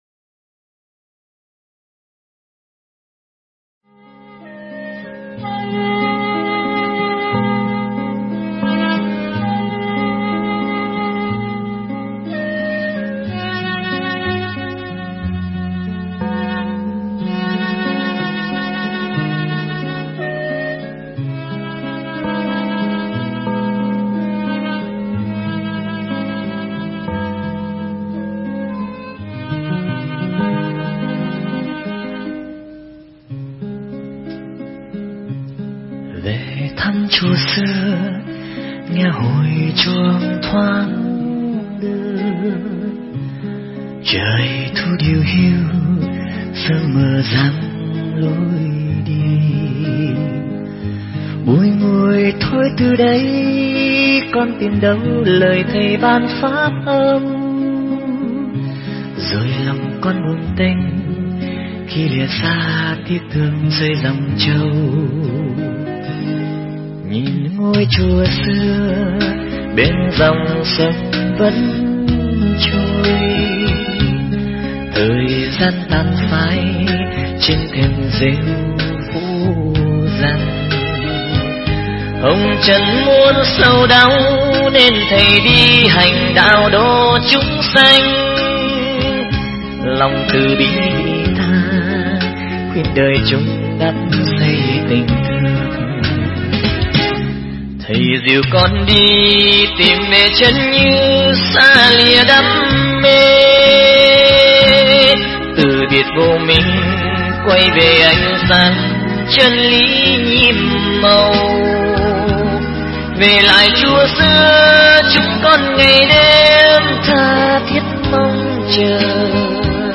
Pháp âm Nuôi Dưỡng Từ Bi Và Bố Thí Trí Tuệ
giảng tại chùa Phước Duyên nhân Tắt Niên ngày 17 tháng 12 năm Bính Tuất (04/02/2007)